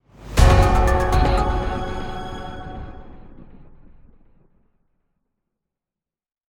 fanfare find-item get-item happy intense item-get level-up mission-complete sound effect free sound royalty free Gaming